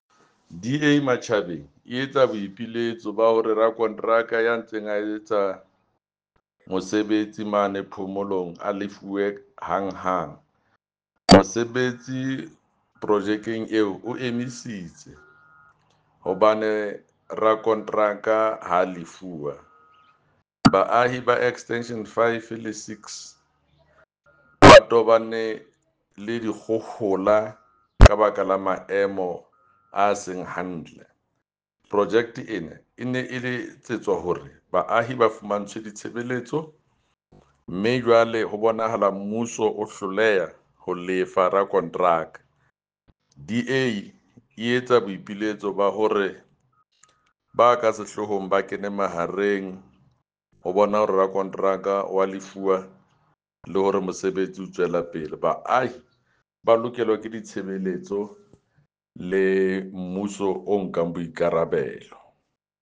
Sesotho soundbite by Jafta Mokoena MPL.